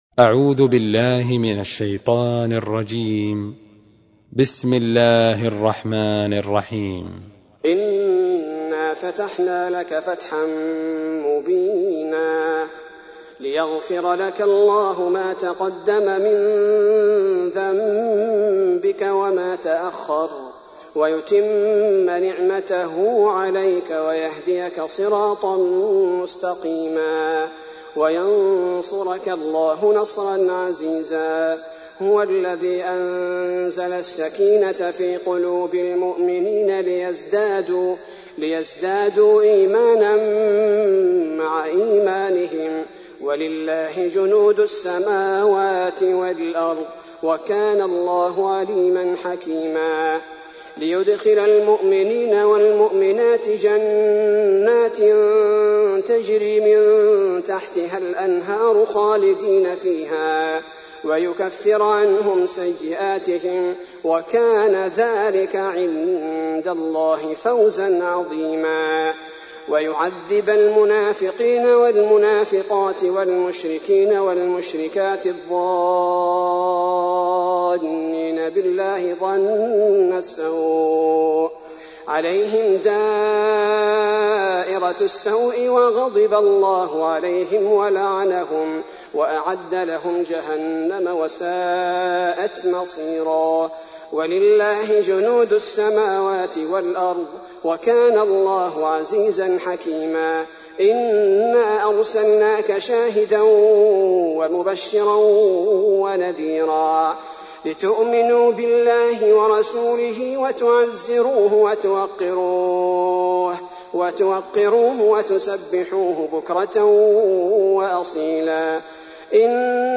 تحميل : 48. سورة الفتح / القارئ عبد البارئ الثبيتي / القرآن الكريم / موقع يا حسين